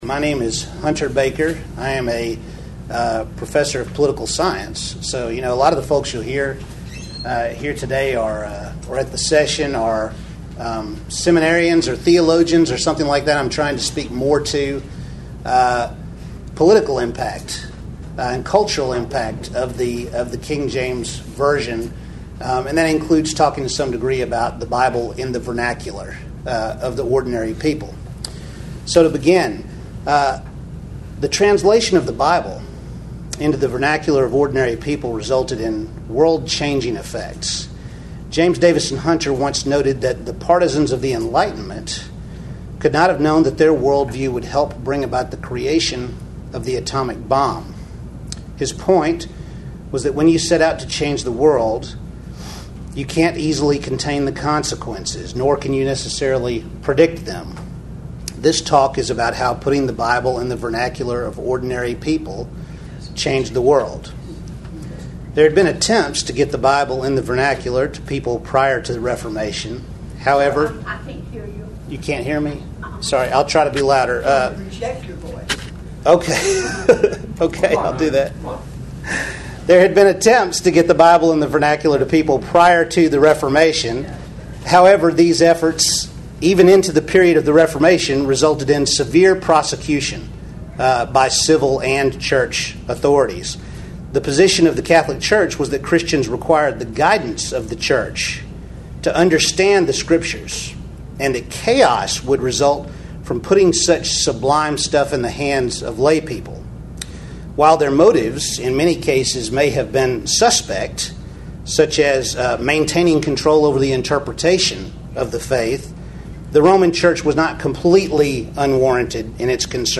Address: The King James Bible: Understanding the Reformation Drive for Literacy & Its Impact on Politics Recording Date